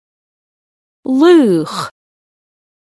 🙂 Here is a sound file for laoch.
It sounds like ‘ree.’